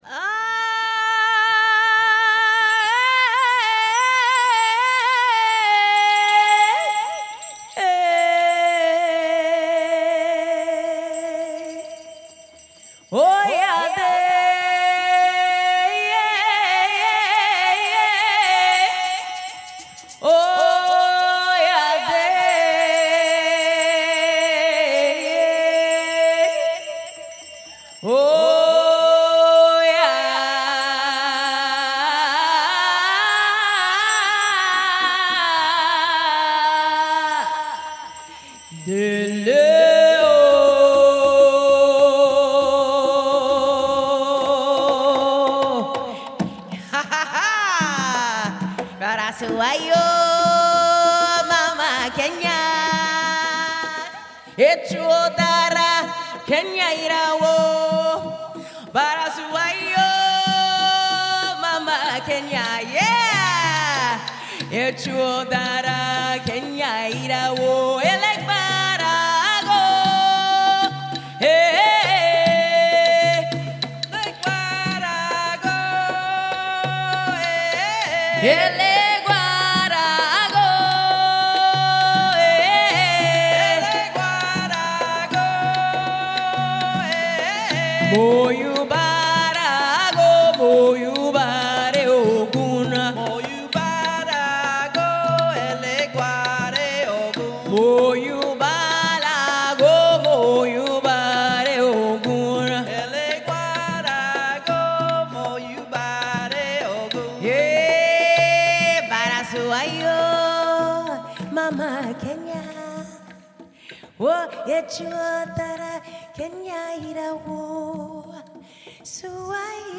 Triumphant magic Bioneers talk